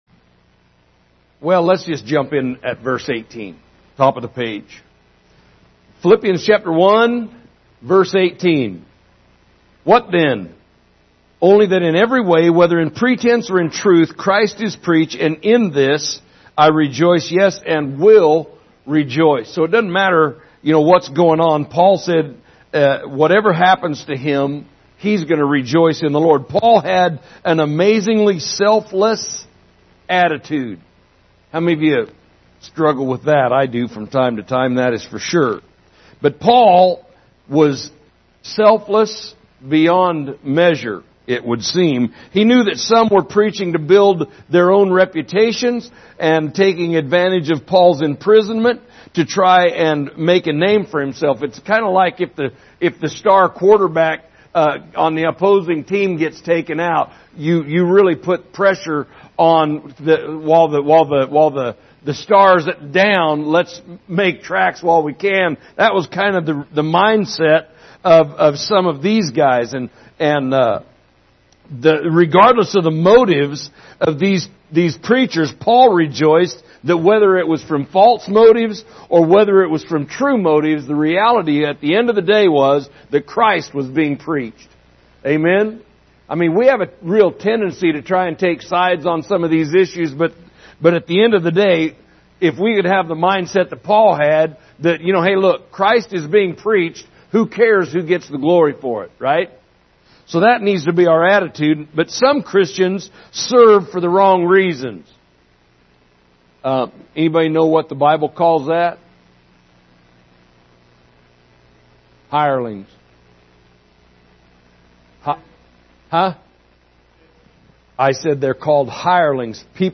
Midweek Service August 18, 2021
Recent Sermons